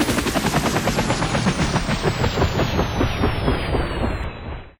helistop.ogg